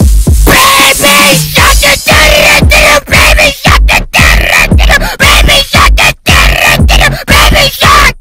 Download baby shark screaming sound button
baby-shark-screaming.mp3